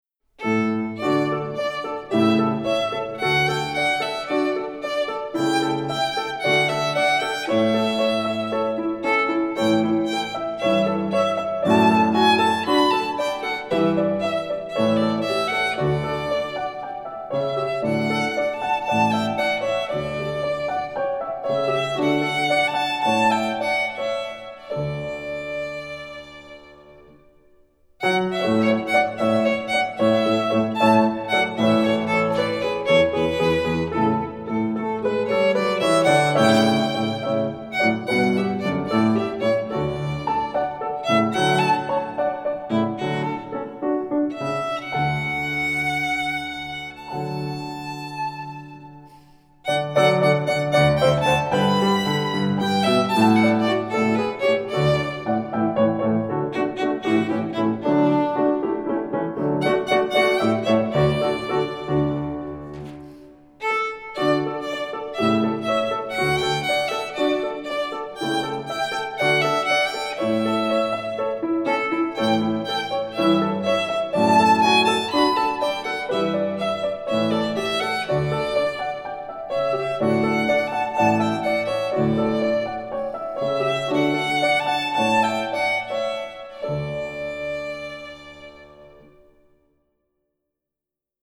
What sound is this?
Voicing: Violin